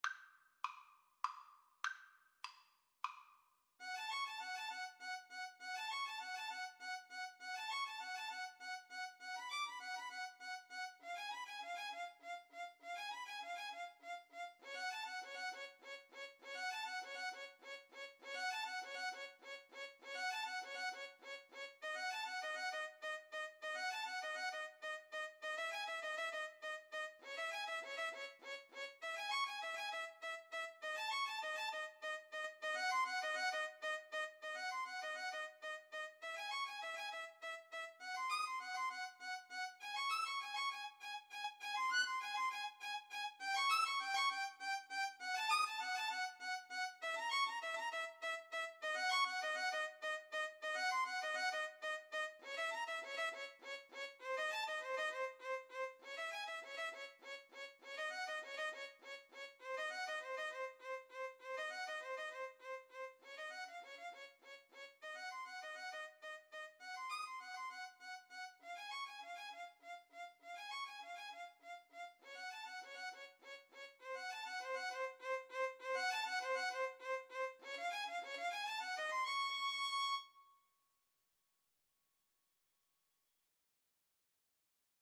Con moto, leggieramente
Classical (View more Classical Violin Duet Music)